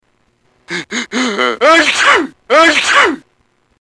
Man Double Sneeze